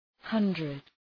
Προφορά
{‘hʌndrıd}